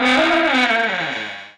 trapdooropen.wav